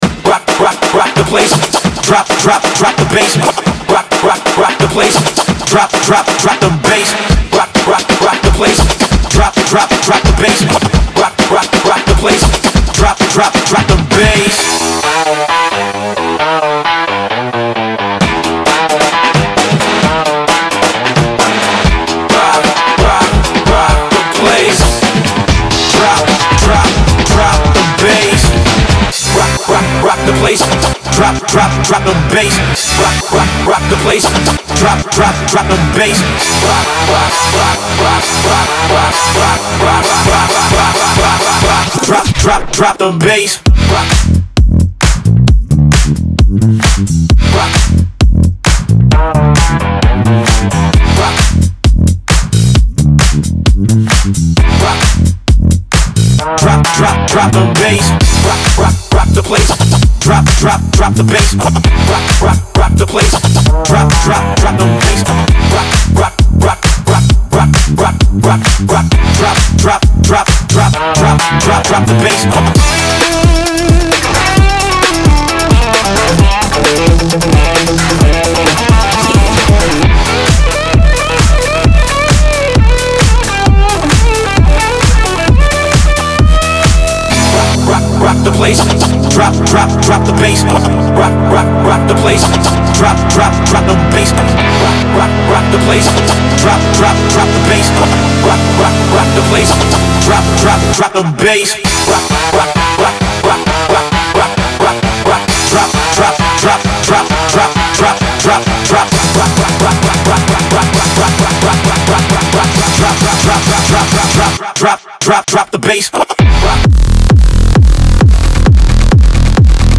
Трек размещён в разделе Зарубежная музыка / Танцевальная.